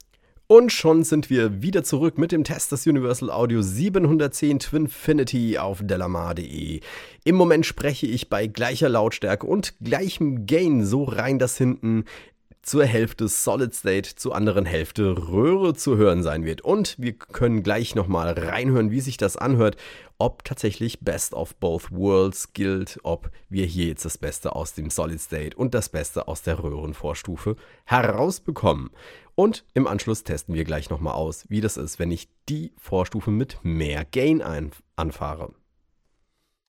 In der Röhrenvorstufe hingegen klingt alles etwas runder und wärmer, der Attack wirkt wie etwas »verschmiert«.
In den Klangbeispielen findest Du zahlreiche Aufnahmen mit unterschiedlichen Einstellungen für Gain und Mischungsverhältnis der beiden Schaltkreise.